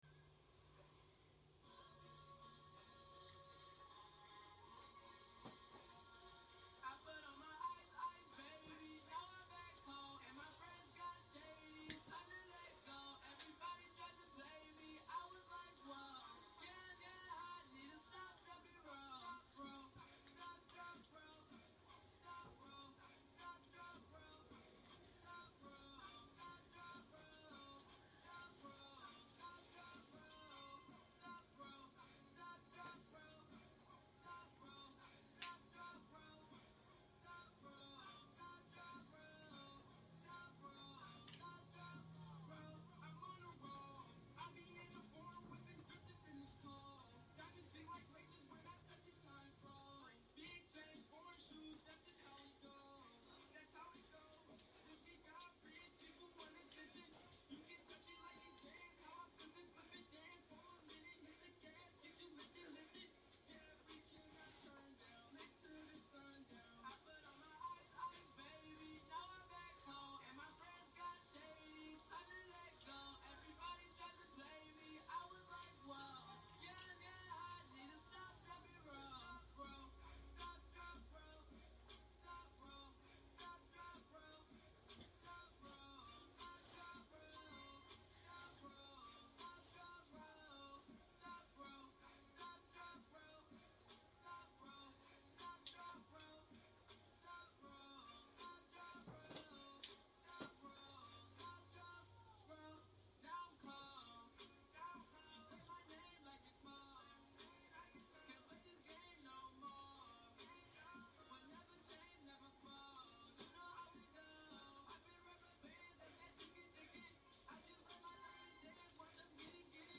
Genres: Hip Hop, Trap, Cloud Rap